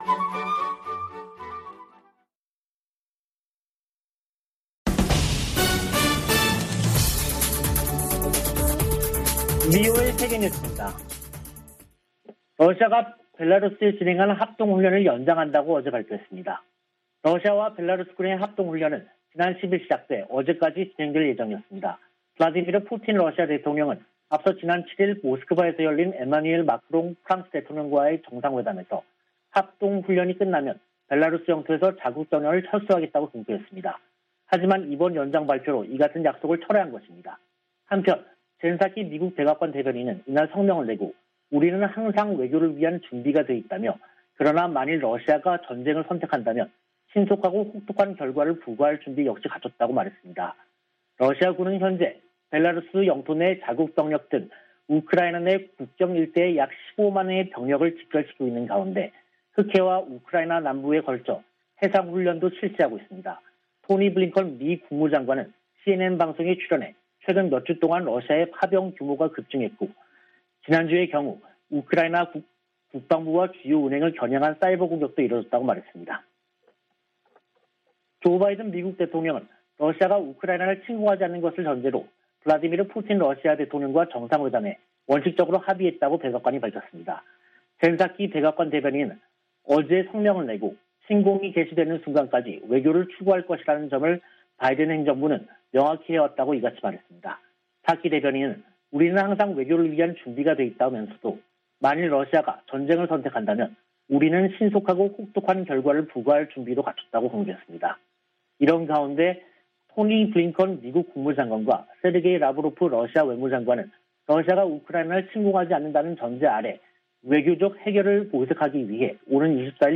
VOA 한국어 간판 뉴스 프로그램 '뉴스 투데이', 2022년 2월 21일 2부 방송입니다. 존 볼튼 전 백악관 국가안보보좌관은 북한 정권 교체 가능성과 핵 프로그램에 대한 무력 사용이 배제돼선 안 된다고 주장했습니다. 북한 비핵화 문제는 미-북 간 최고위급 논의가 필요하다고 마이크 폼페오 전 미 국무장관이 말했습니다. 한국의 북한 출신 국회의원들이 유엔 북한인권특별보고관에게 북한 반인도 범죄자들에 고소·고발 지원을 요청했습니다.